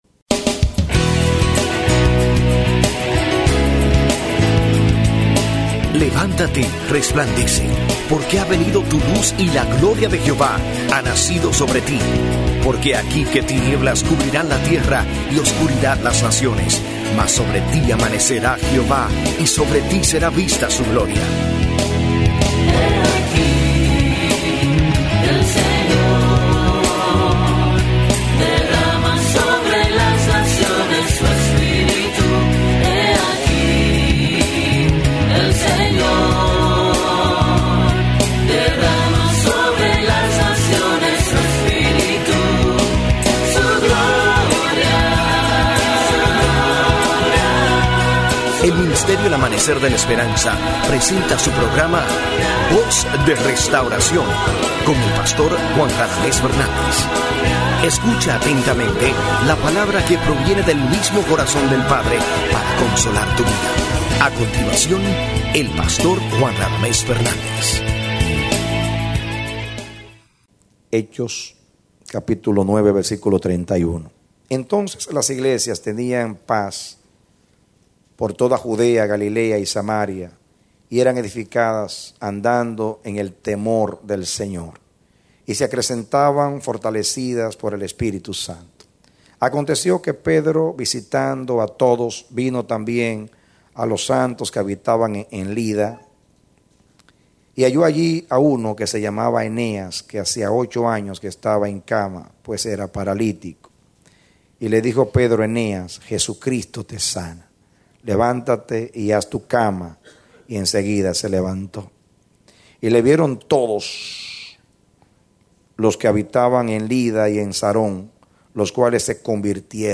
A mensaje from the serie "La Iglesia Saludable." Solo Audio